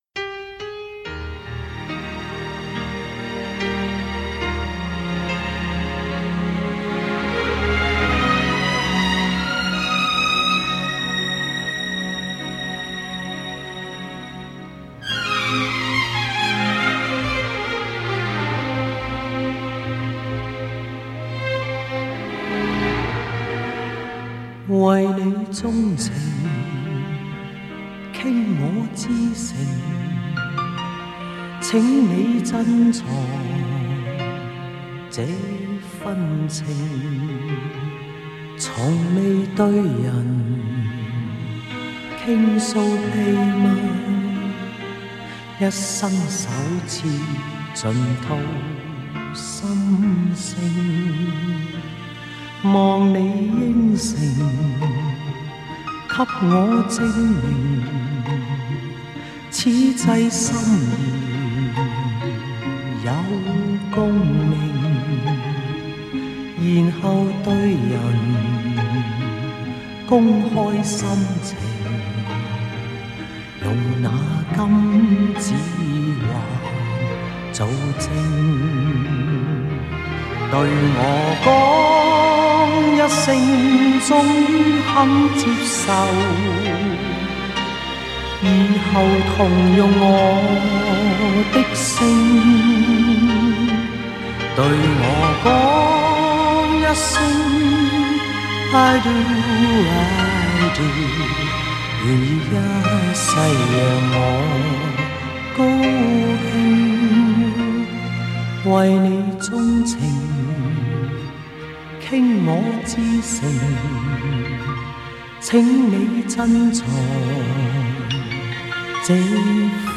如假包换都是些舒缓节奏的曲子